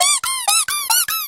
squeaky_bomb_tick_01.ogg